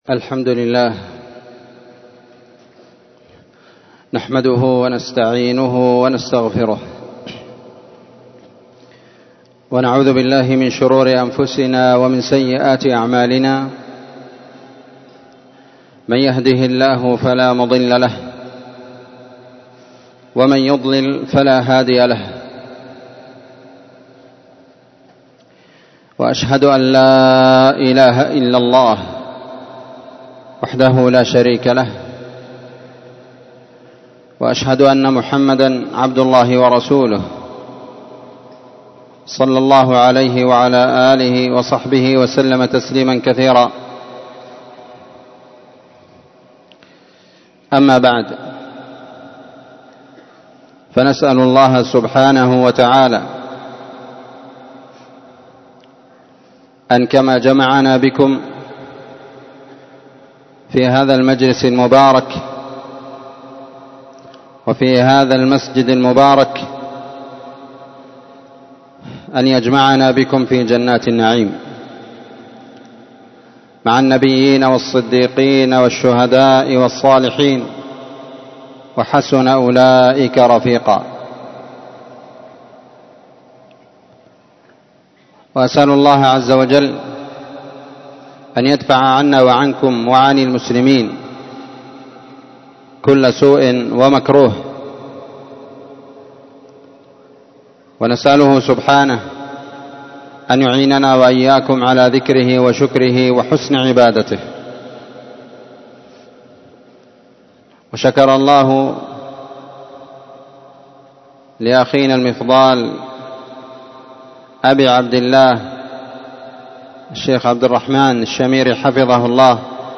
محاضرة بعنوان( تأملات في قول المولى سبحانه {واتل عليهم نبأ الذي آتيناه آياتنا فانسلخ منها} الآية) ليلة ٧ شعبان ١٤٤٥ه‍.
مسجد المجاهد - تعز- اليمن.